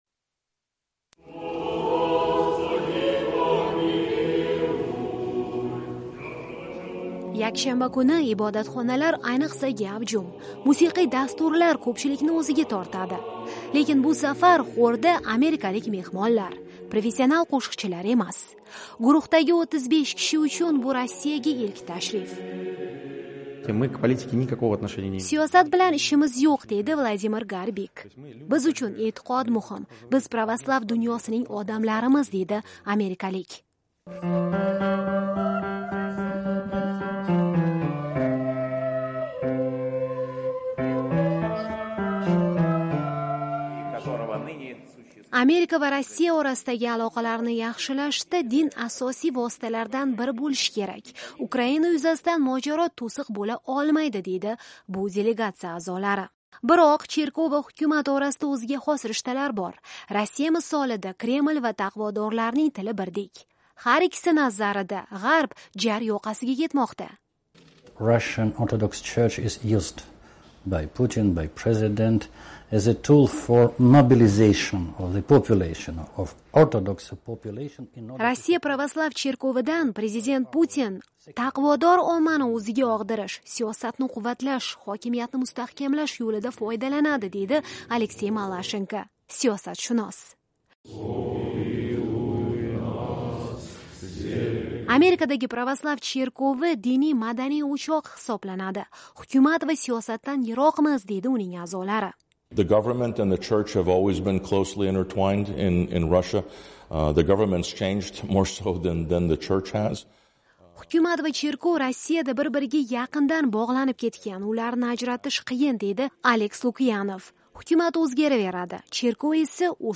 Lekin bu safar xorda amerikalik mehmonlar. Professional qo’shiqchilar emas.